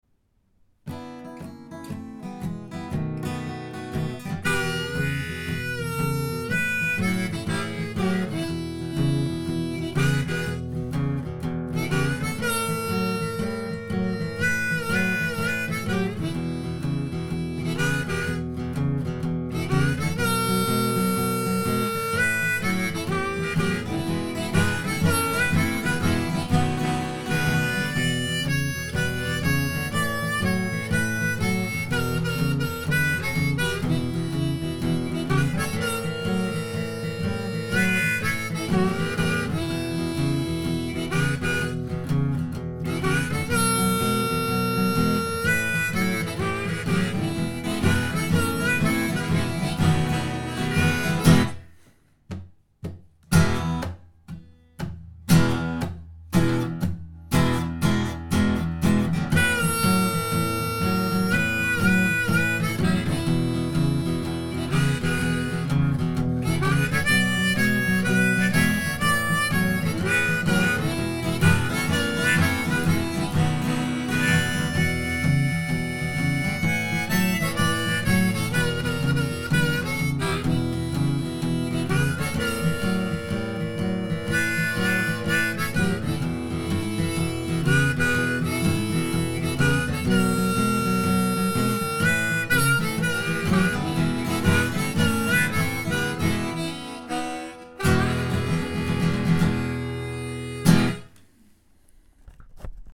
Cet a-m quartier libre aussi j'ai enregistré quelques bouts de musique avec ma D-35 récente.
Pas vraiment satisfait du son de l'enregistrement (c'est brut, c'est muddy, je ne sais pas mixer :cry: ), quelques pains et faiblesses rythmiques, mais bon pour donner un aperçu autant de la D-35 que d'un harmonica Lee Oskar (le tout enregistré ensemble, j'ai pas triché ! :lol: )
La D-35 se prête merveilleusement bien à ce jeu bluesy.